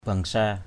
bengsa.mp3